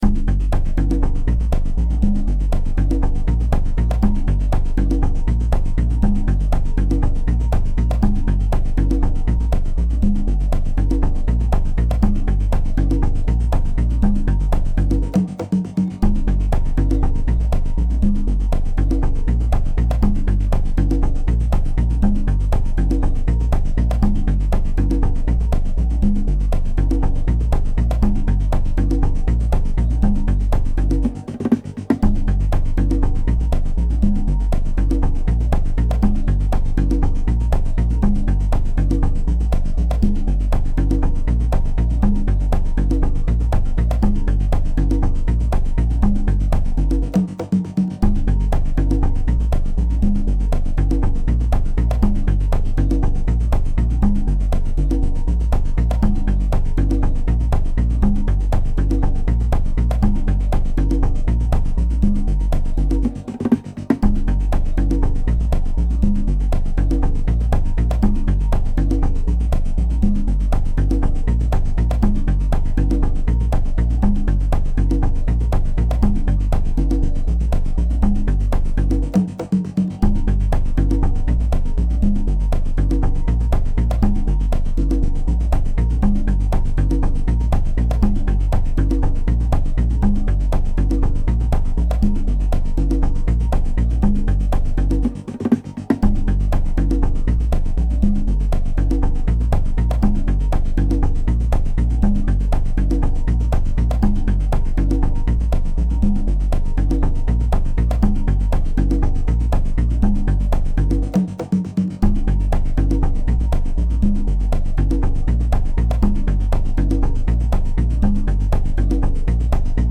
ドラムが消えて少しノリが和らいでいます。